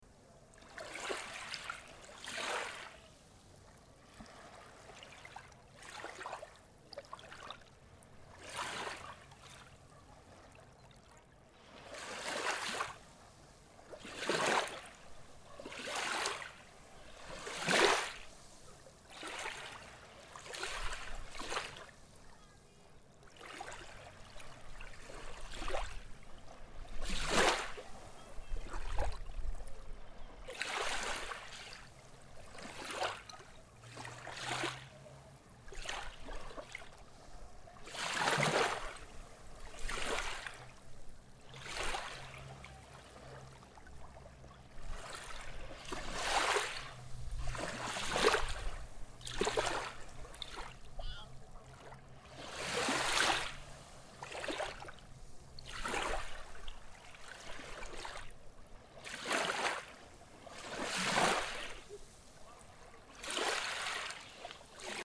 Sea at night
Late at night on the beach at Port de Sóller, Mallorca
Listen to the waves at Port de Sóller, Mallorca, Spain.
Recorded on Mai 18. 2017 with iPhone 7 and Rode iXY.